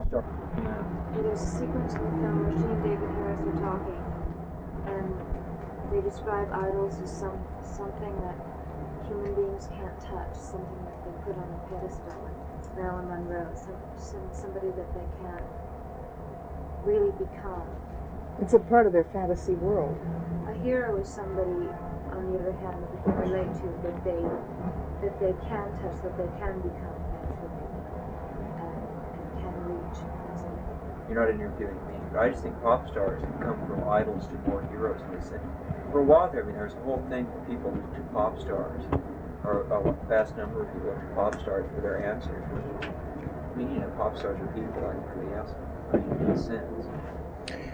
14 Pop Idols and Rock Stars (The Lost Interview Tapes - Volume Two).flac